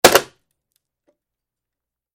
Пробили пластик или дерево тяжелым ломом